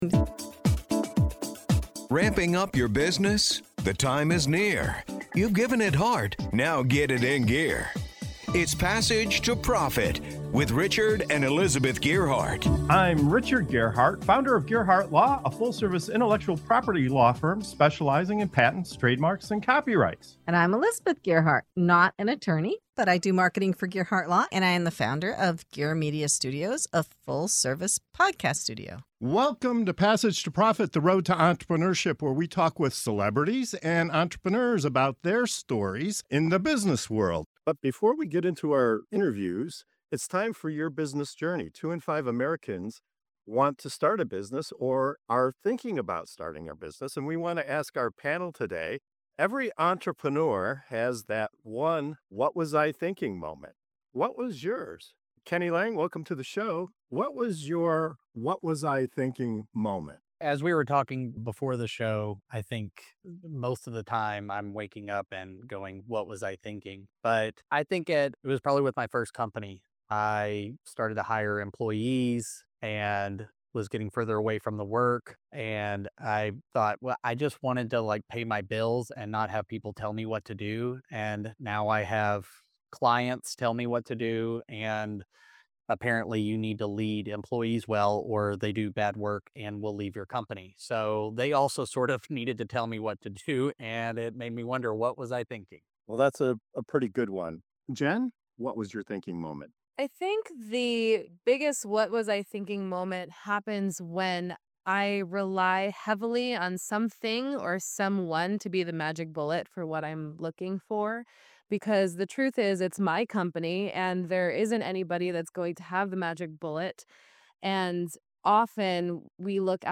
This segment of "Your New Business Journey" on the Passage to Profit Show dives into the hilarious, humbling, and oh-so-relatable “What Was I Thinking?” moments every entrepreneur faces. Our panel of business owners share their most surprising mistakes, from hiring mishaps to magic-bullet thinking to grand opening nightmares, and how those moments ultimately shaped them as better leaders.